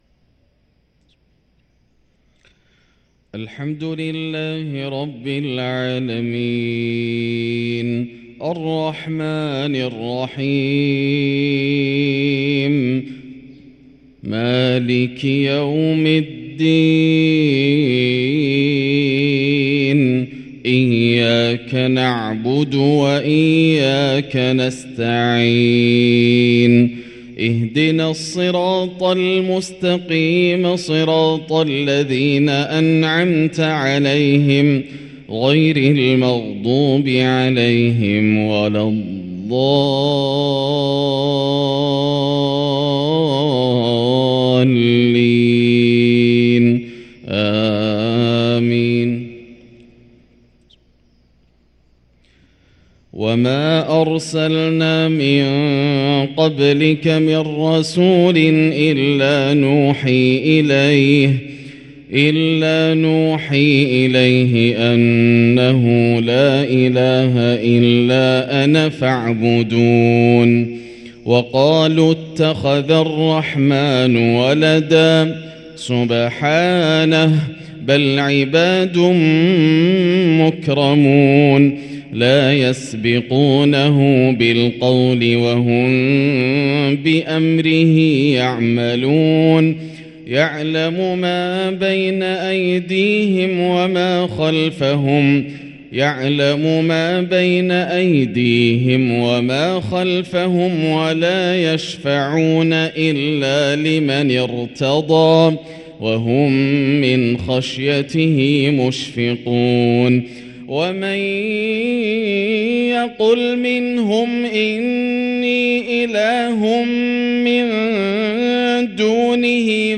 صلاة الفجر للقارئ ياسر الدوسري 23 شعبان 1444 هـ
تِلَاوَات الْحَرَمَيْن .